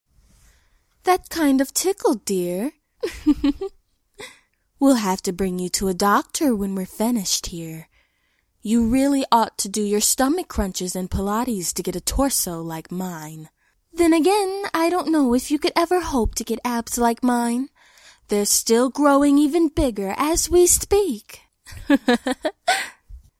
This has over 9 minutes of pure FMG audio recorded by two separate talented actresses, and edited + compiled by me.